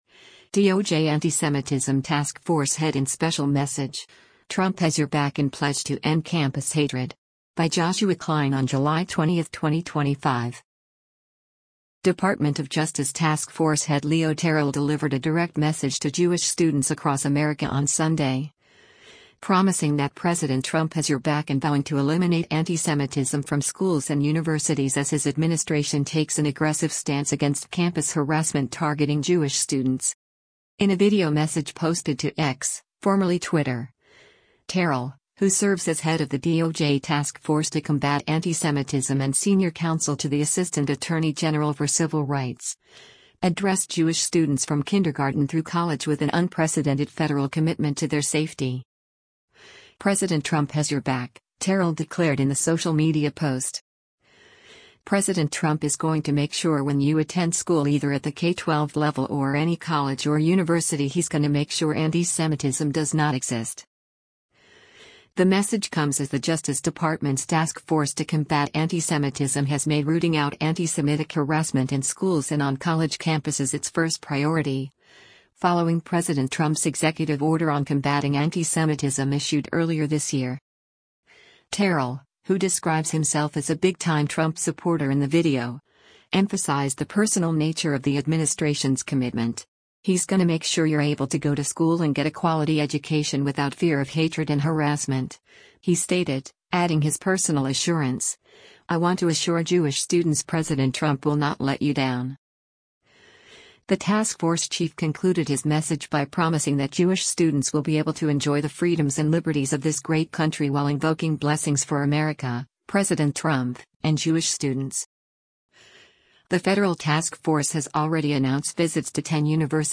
Department of Justice task force head Leo Terrell delivered a direct message to Jewish students across America on Sunday, promising that President Trump “has your back” and vowing to eliminate antisemitism from schools and universities as his administration takes an aggressive stance against campus harassment targeting Jewish students.